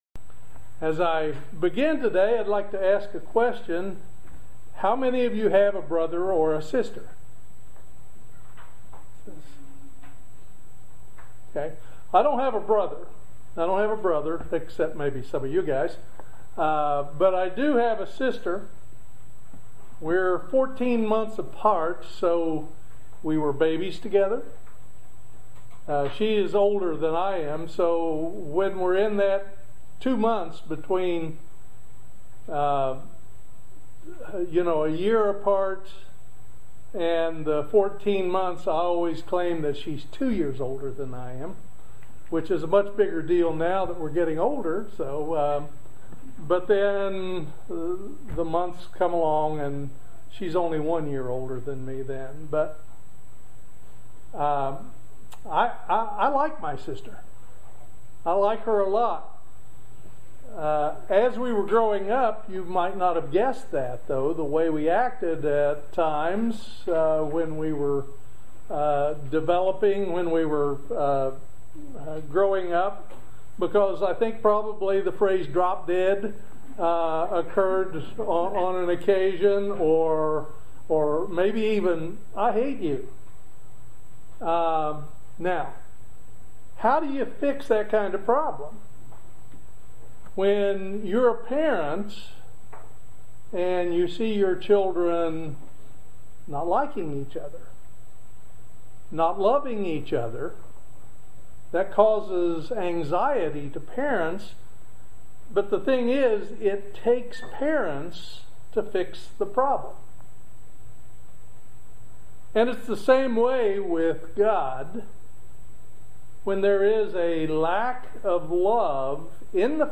Join us for this very important sermon on the subject of God's Love. Did you know there are different kinds of love?
Given in Lexington, KY